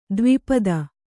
♪ dvi pada